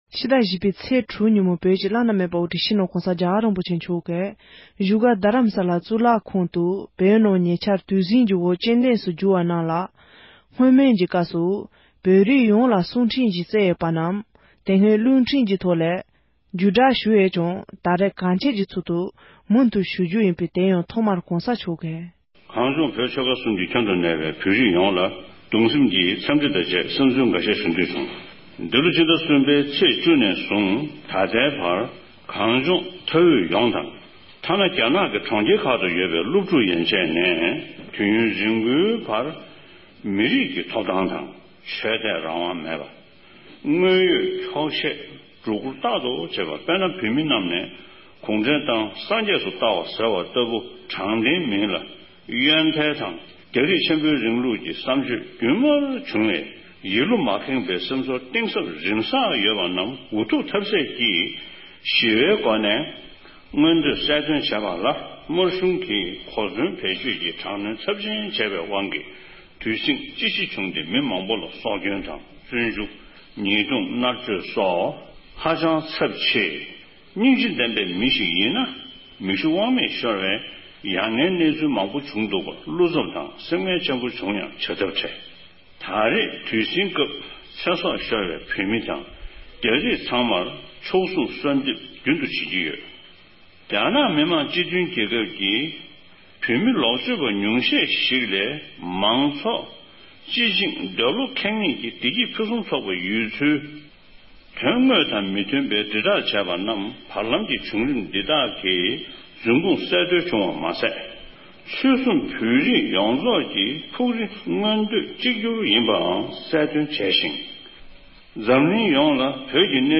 ཁམས་སྐད་ཐོག་ཕྱོགས་སྒྲིག་བྱས་པར་གསན་རོགས་ཞུ༎